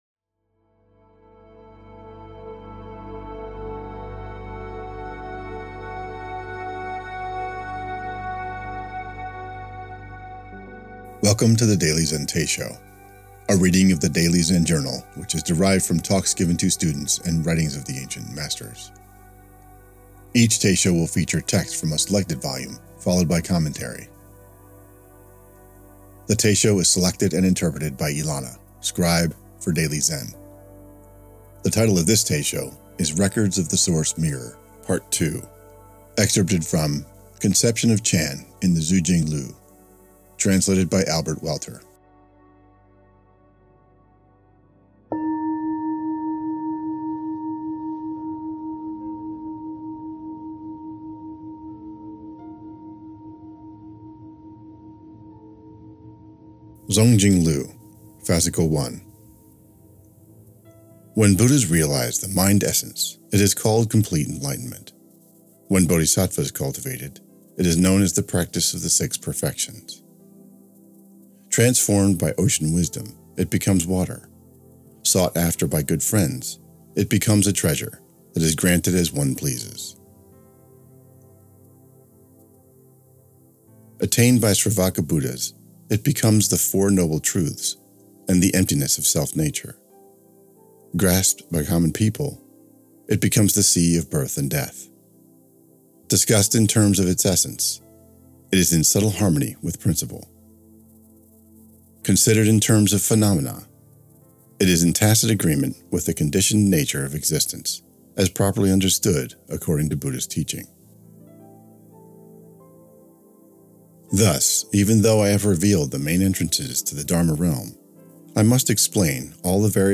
The Daily Zen Teisho Records of the Source Mirror - Part 2 Play Episode Pause Episode Mute/Unmute Episode Rewind 10 Seconds 1x Fast Forward 30 seconds 00:00 / 8:46 Download file | Play in new window | Duration: 8:46